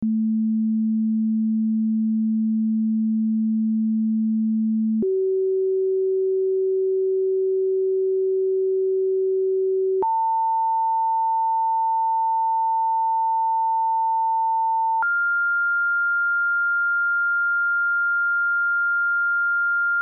Con un software particolare sono stati creati questi suoni con la seguente frequenza:
• suono n. 1: 220 Hz
• suono n. 2: 380 Hz
• suono n. 3: 900 Hz
• suono n. 4: 1400 Hz
Come puoi facilmente notare, i suoni diventano progressivamente più acuti man mano che cresce la loro frequenza